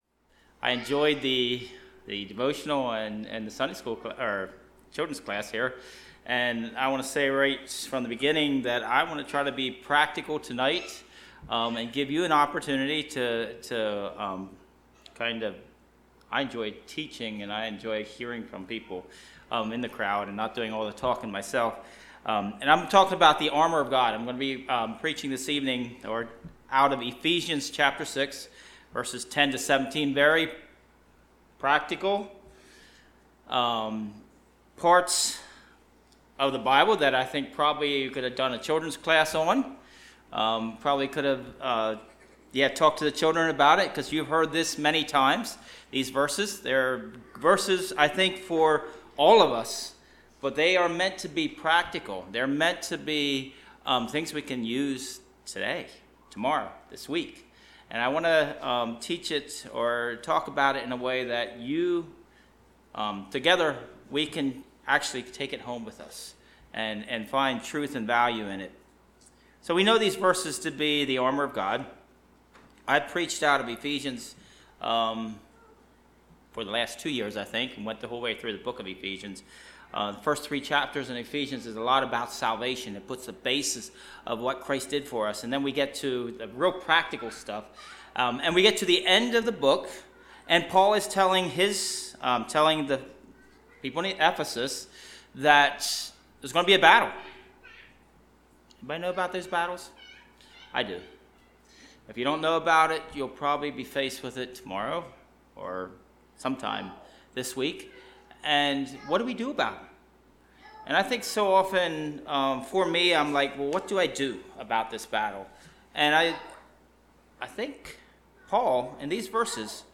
Passage: Ephesians 6:10-17 Service Type: Sunday Evening